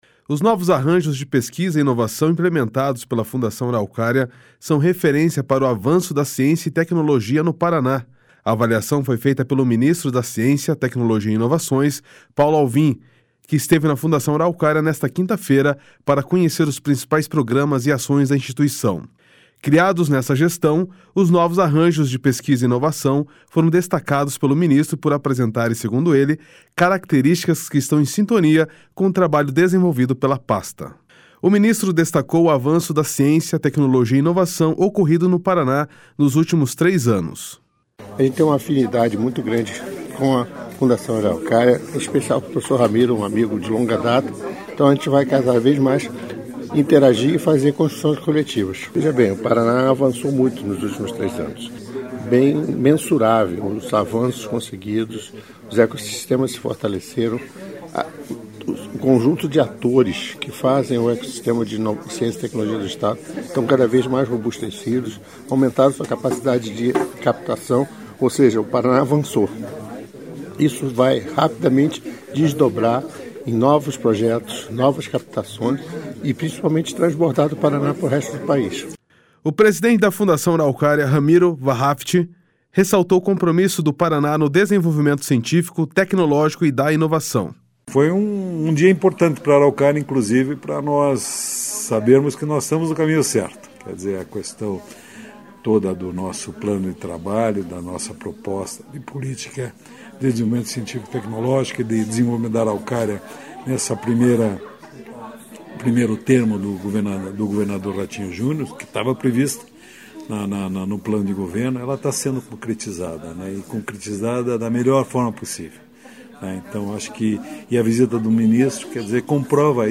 O ministro destacou o avanço da ciência, tecnologia e inovação ocorrido no Paraná nos últimos três anos.//SONORA PAULO ALVIM.//
O presidente da Fundação Araucária, Ramiro Wahrhaftig ressaltou o compromisso do Paraná no desenvolvimento científico, tecnológico e da inovação.//SONORA RAMIRO WAHRHAFTIG.//